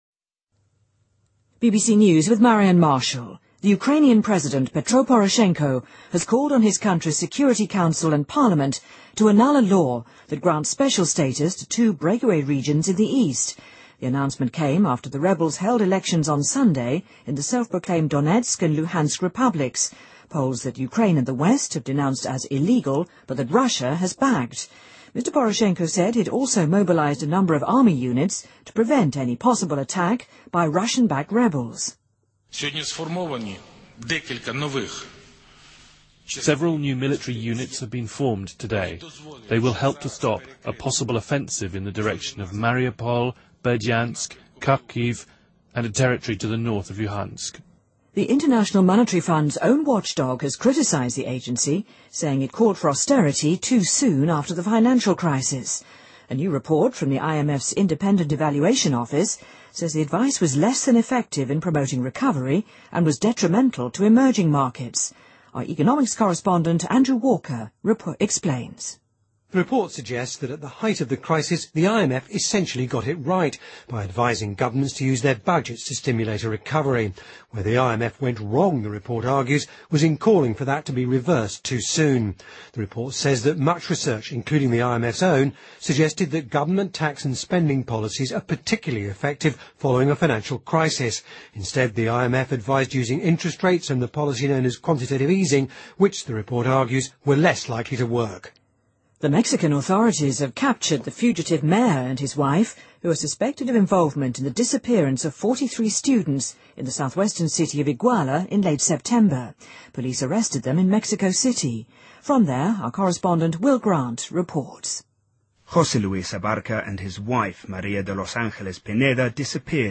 BBC news,西班牙加泰罗尼亚地区当局誓言要继续本周末象征性的独立选举投票